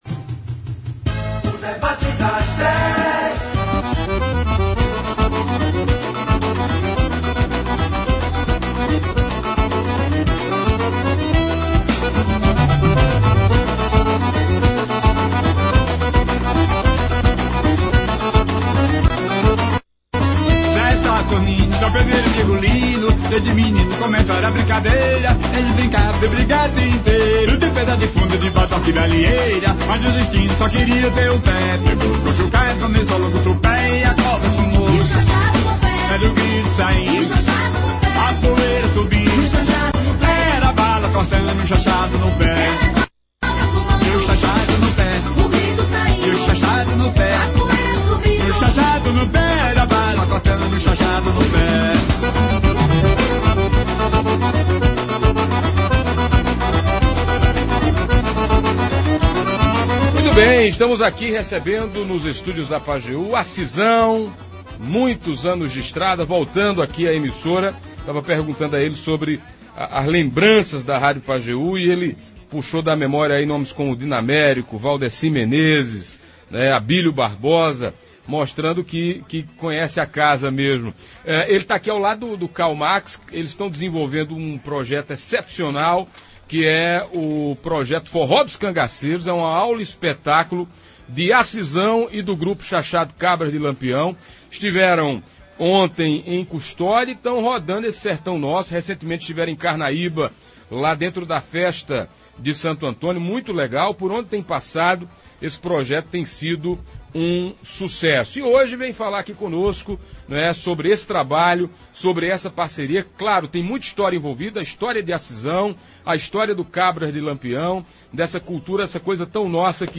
Ouça abaixo na íntegra como o debate de hoje: